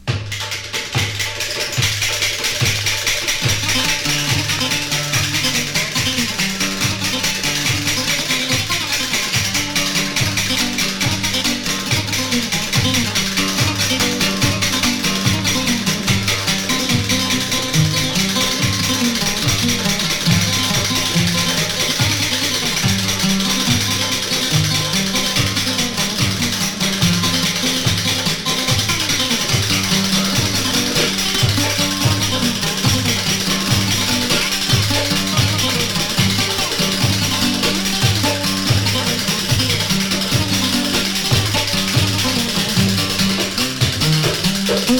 独特とも神秘的とも思えるエキゾティシズムをまとった魅力的な楽曲が緩急自在な演奏によりうねります。
※盤質によるプチプチノイズ有
World, Middle East　USA　12inchレコード　33rpm　Stereo
盤擦れ　プチプチノイズ